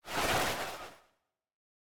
Minecraft Version Minecraft Version snapshot Latest Release | Latest Snapshot snapshot / assets / minecraft / sounds / mob / breeze / slide4.ogg Compare With Compare With Latest Release | Latest Snapshot
slide4.ogg